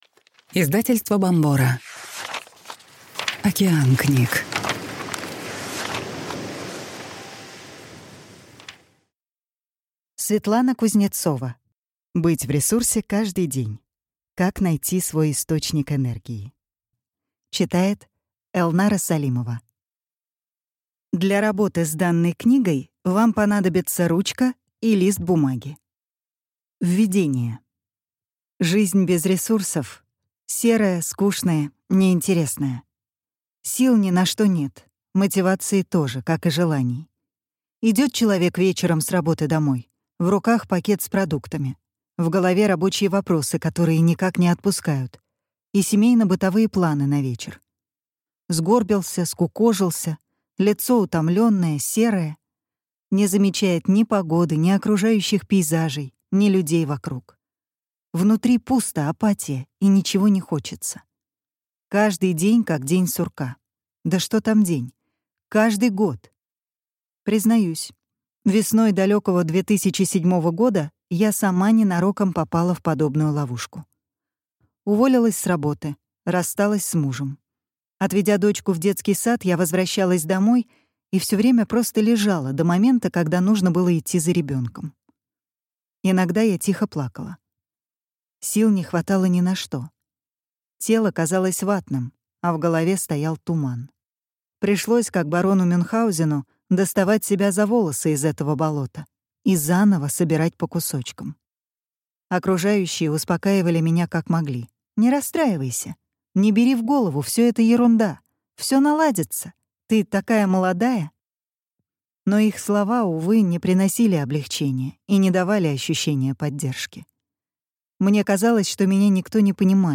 Аудиокнига Быть в ресурсе каждый день. Как найти свой источник энергии | Библиотека аудиокниг